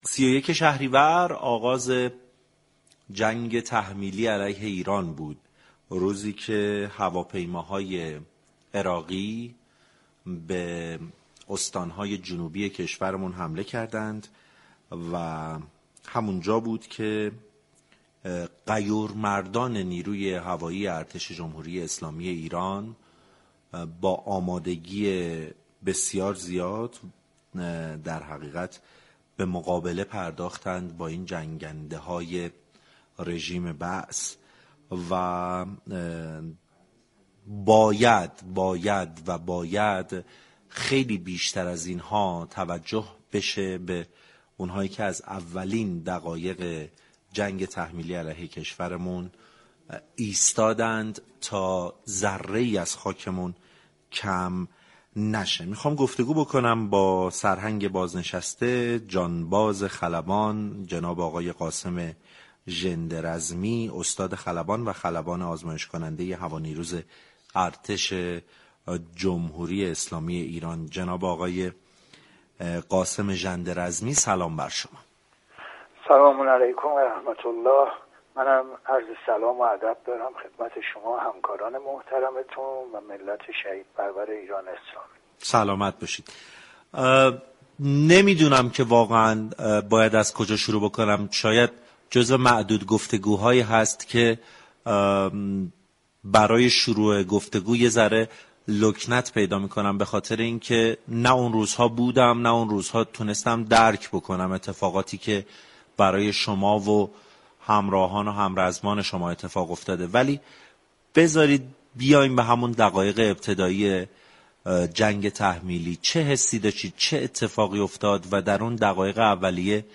در گفتگو با برنامه سعادت آباد تهران به مناسبت گرامیداشت هفته دفاع مقدس